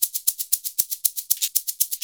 Index of /90_sSampleCDs/USB Soundscan vol.56 - Modern Percussion Loops [AKAI] 1CD/Partition D/04-SHAKER119